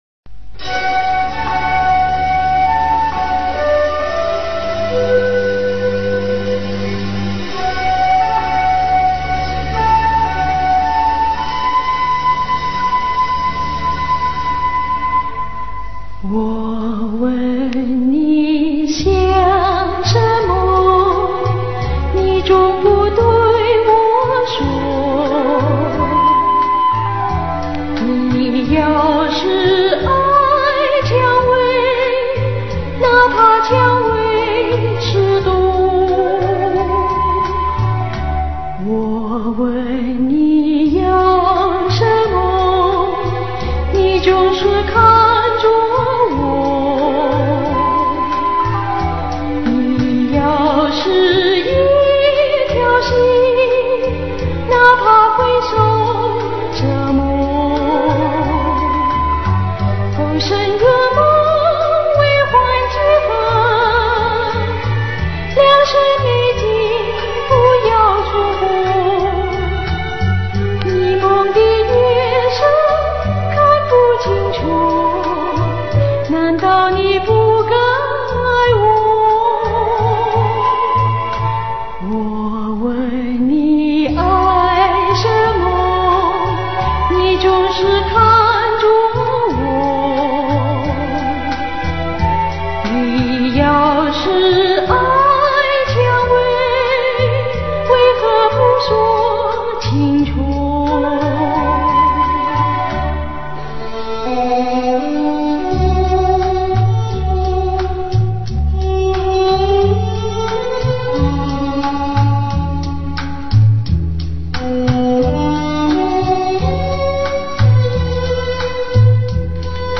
网友要是细心听，歌者唱 "良辰美景" 一句中的 "辰"字，发音可能不是普通话的标准发音！】